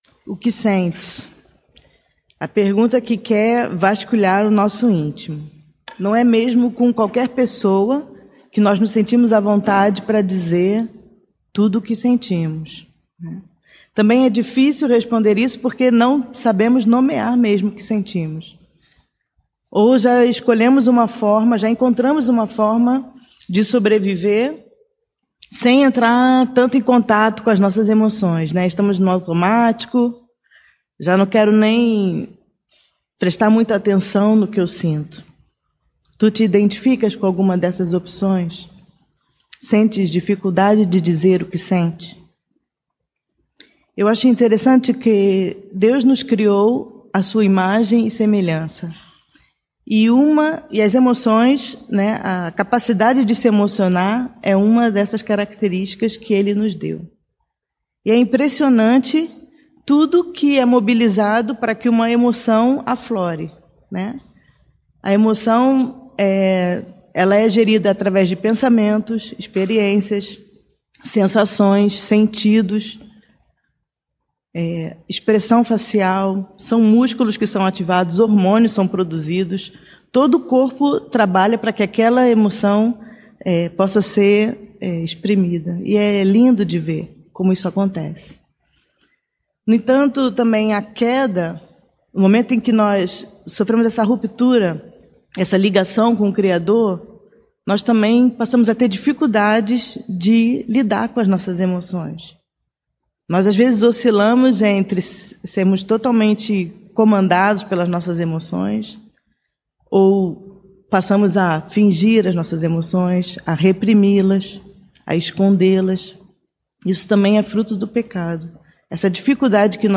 perguntas (in)convenientes mensagem bíblica Como te sentes?